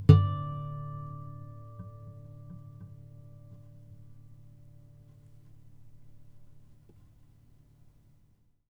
harmonic-05.wav